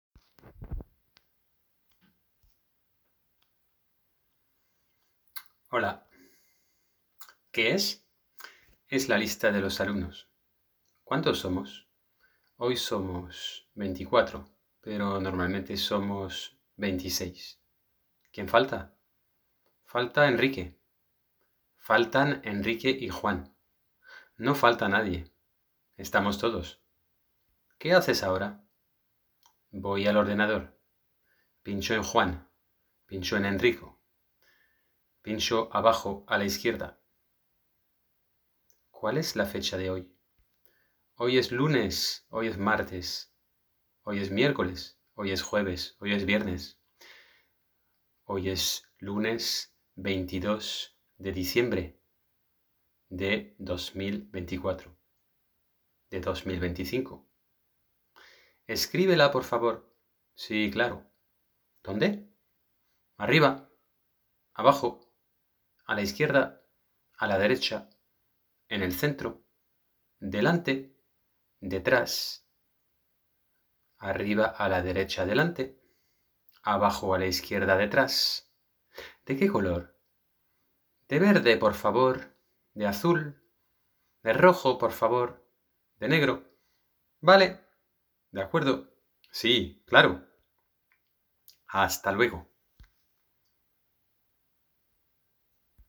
Possobilité d'écouter un exemple du professeur exercice évolutif
ESCUCHA EL PROFESOR: